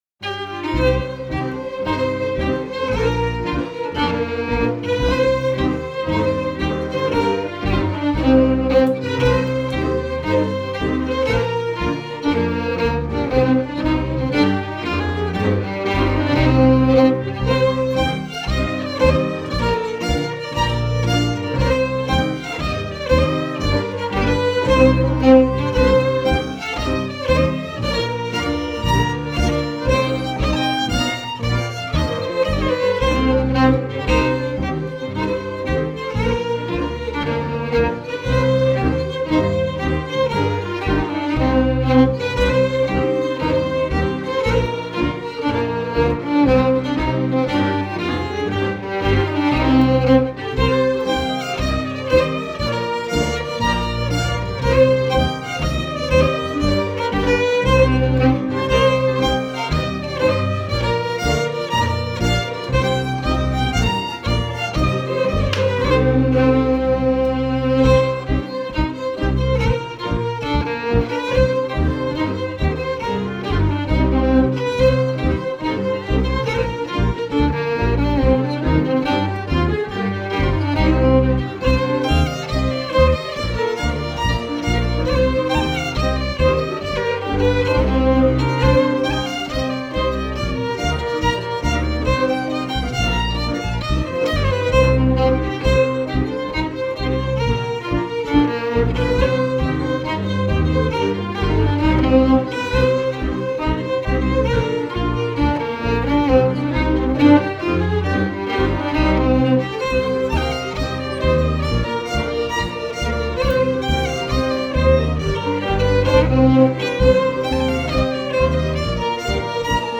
strathspey / reel